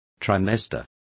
Complete with pronunciation of the translation of trimester.